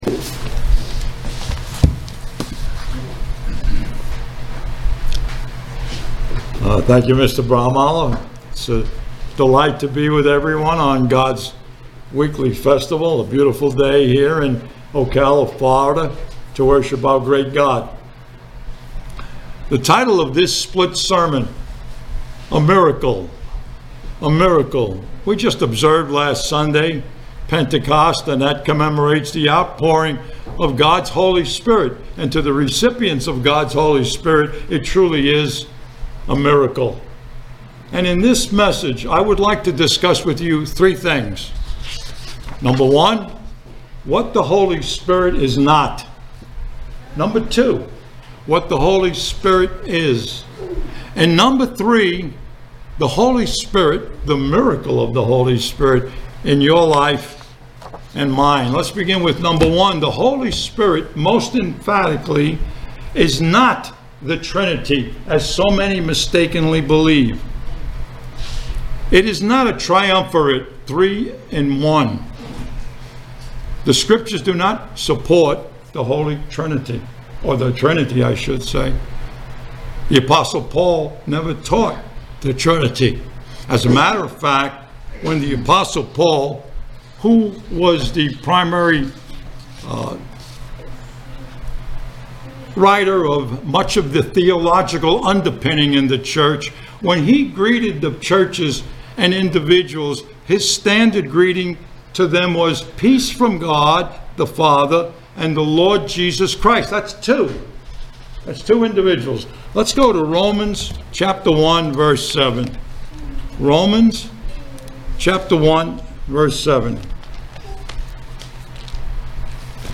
Given in Ocala, FL